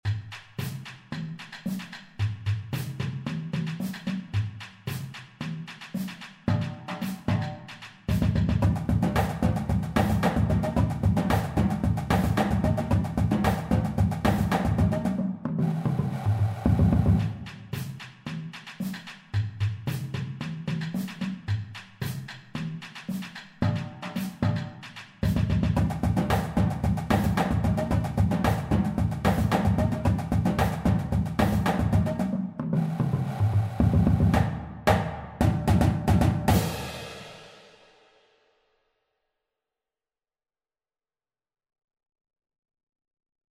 Voicing: Percussion Cadence